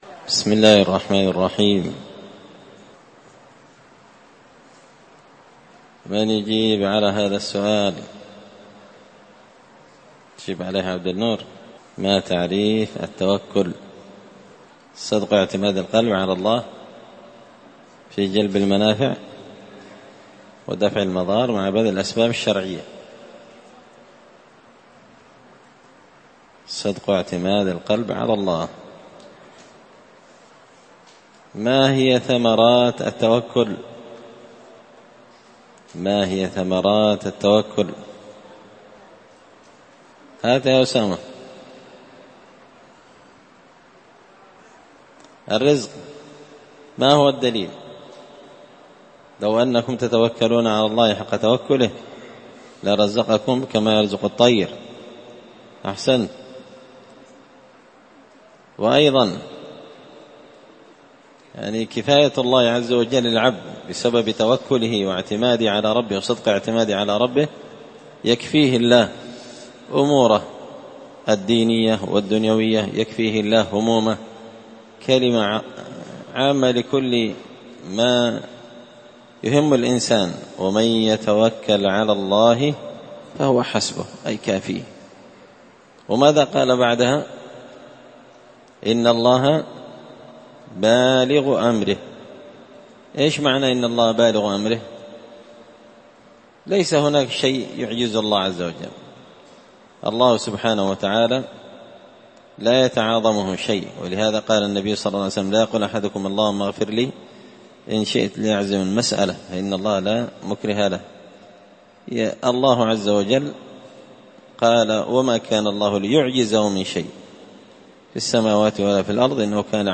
كلمة بعنوان من ثمرات التوكل على الله 16 ذو القعدة 1444هـ
دار الحديث بمسجد الفرقان ـ قشن ـ المهرة ـ اليمن